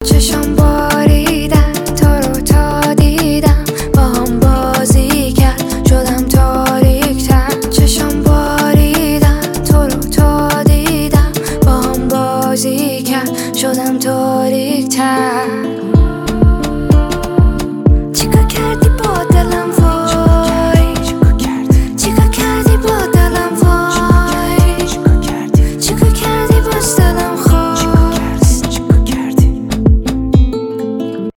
поп , красивый женский голос